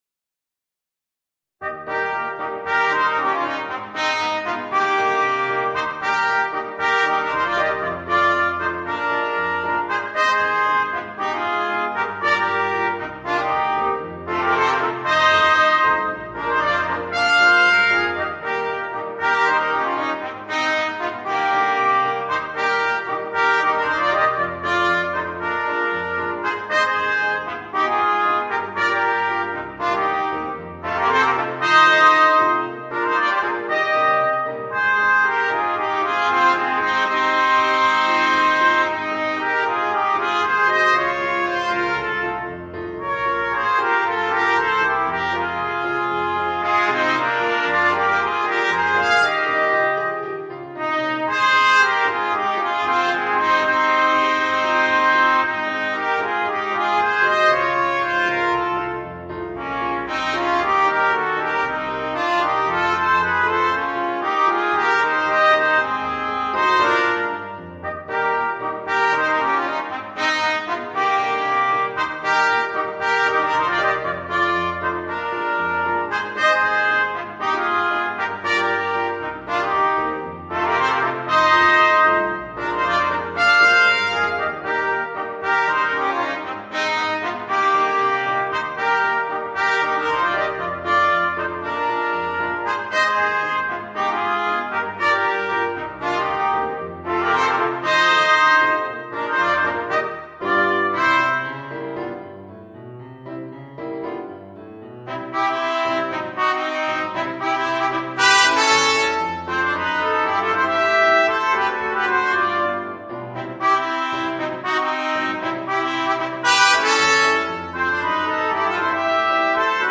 Voicing: 2 Trumpets and Piano